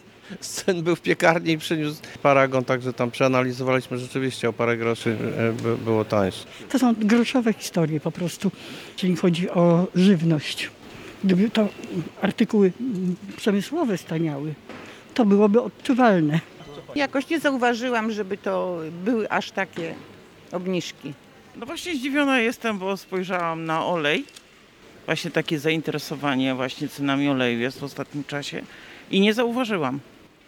Zerowy VAT na żywność. Czy nasze zakupy będą tańsze? [SONDA]
Przed jednym ze sklepów zapytaliśmy powracających z zakupów, czy zauważyli niższy rachunek płacąc przy kasie.